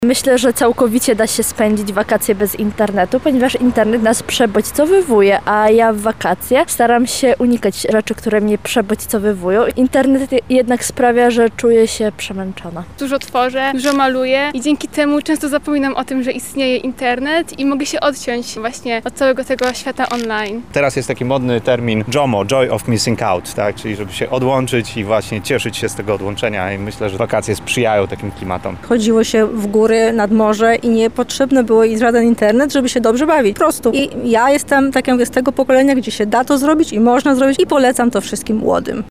Sonda
Sonda-z-mieszkancami-Lublina.mp3